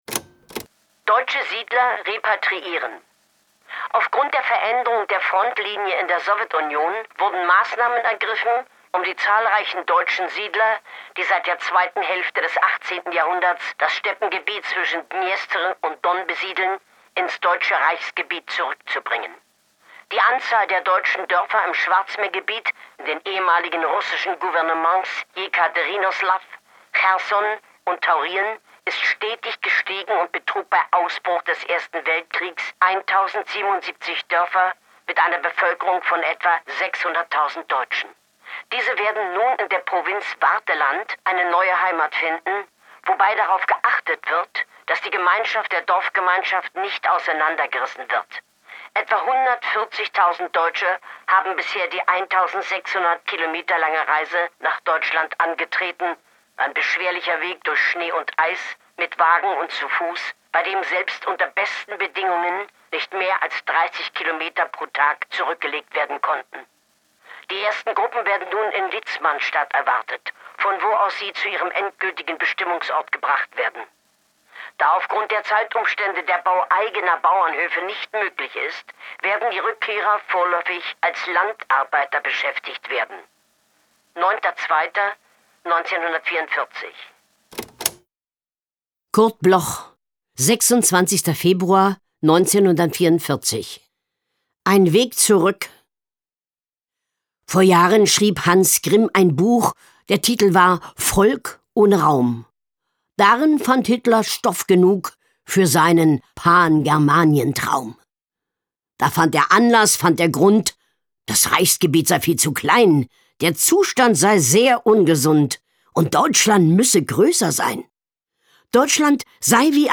Recording: Speak Low, Berlin · Editing: Kristen & Schmidt, Wiesbaden
Carmen-Maja Antoni (* 1945) is een Duitse toneelspeelster en hoorspelactrice.